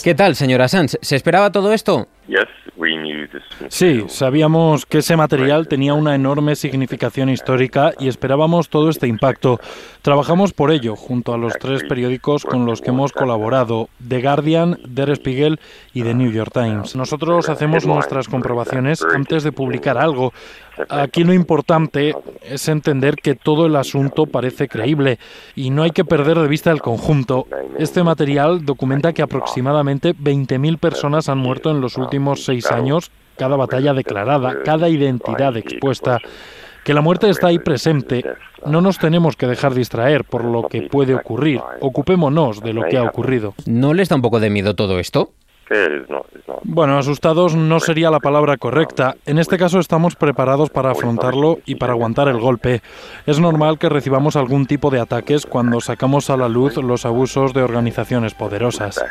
Entrevista telefònica a Julian Assange, dies després de la filtració a la premsa de Wiki Leaks
Informatiu